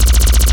Alien Handgun
LASRGun_Alien Handgun Loop_SFRMS_SCIWPNS.wav